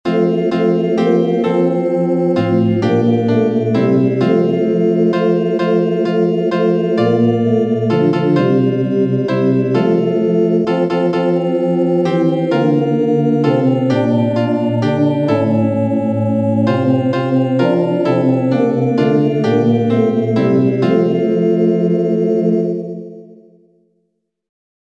Music: Orientis Partibus, medieval French melody